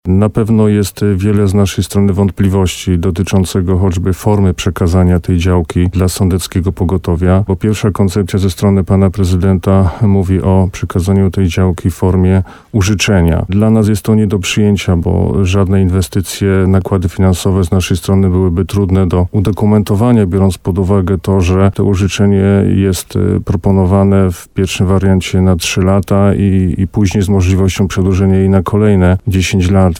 mówił w programie Słowo za Słowo na antenie RDN Nowy Sącz